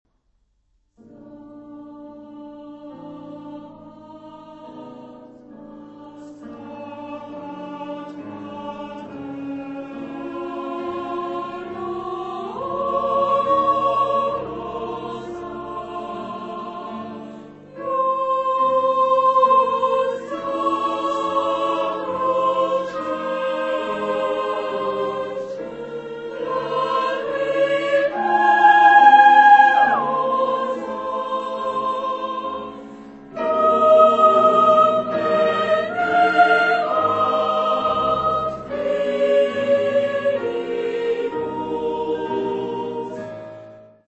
Género/Estilo/Forma: Sagrado ; contemporáneo
Tipo de formación coral: Coro mixto
Solistas : Baryton (1)  (1 solista(s) )
Tonalidad : politonal